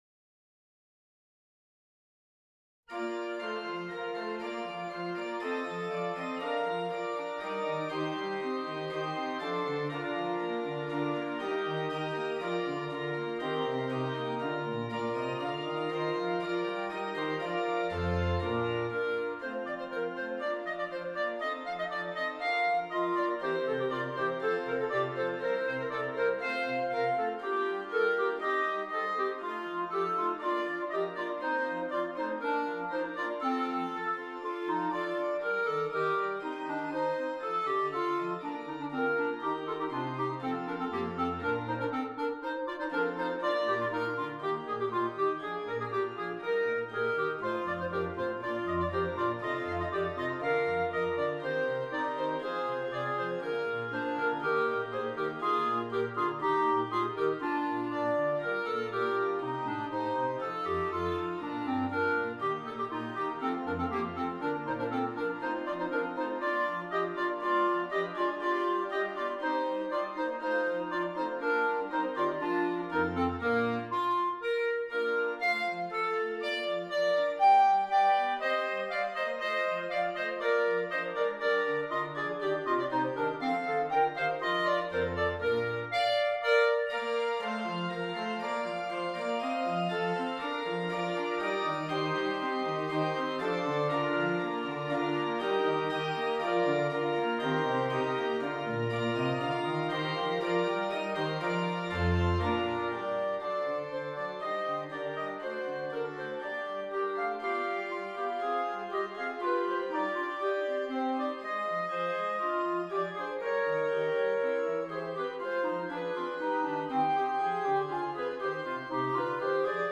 • 2 Clarinets and Keyboard